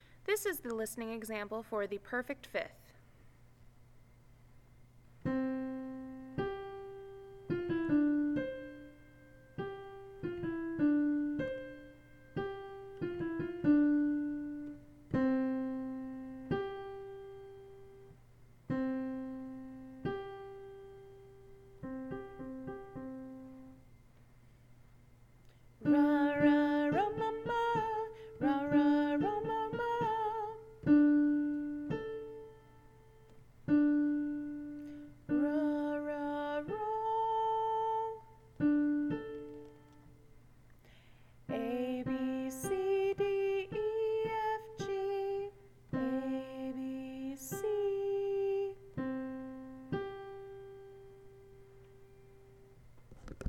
Perfect 5th
Reference songs: Perfect 5th
7_perfect_5th.mp3